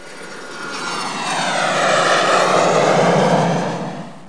samolot2.mp3